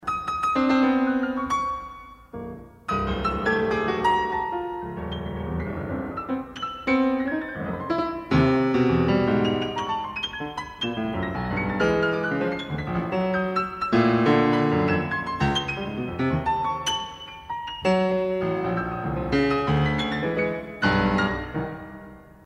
had a sense of effortless swing
sizzling phrase